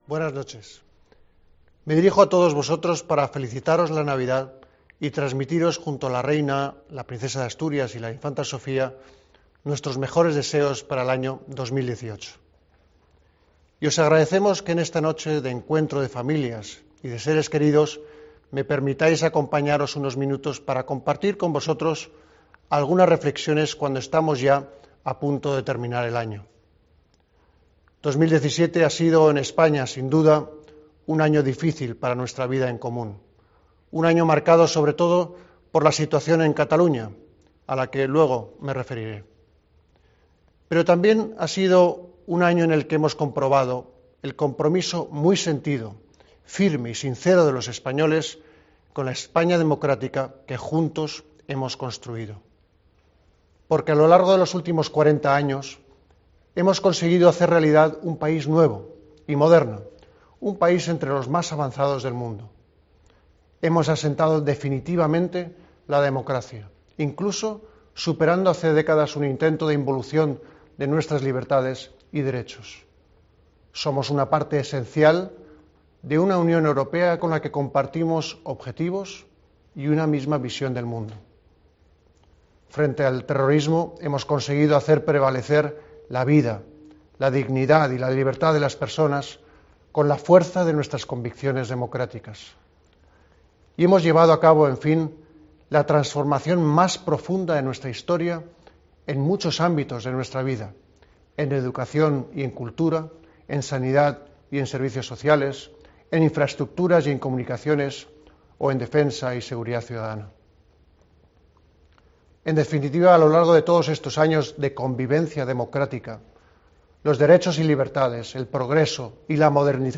Escucha aquí el Mensaje de Navidad de Su Majestad el Rey de 2017